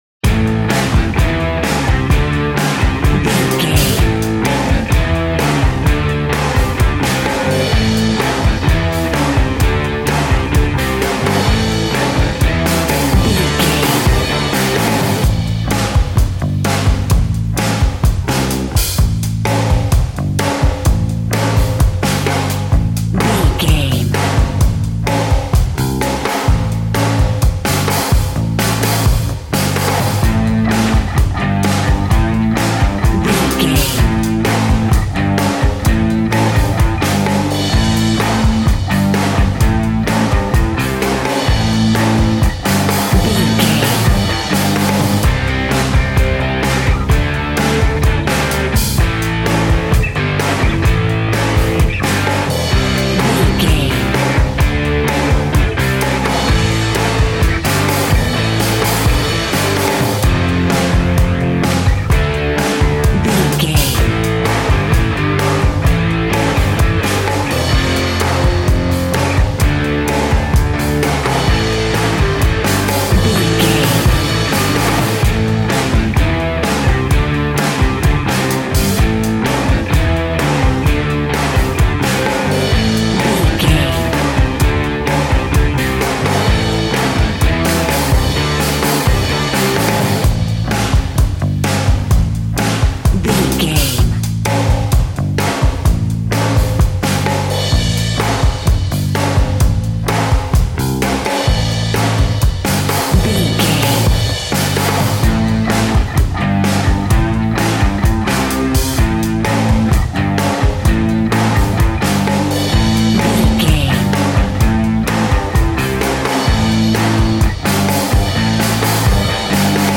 Ionian/Major
D♭
hard rock
distortion
instrumentals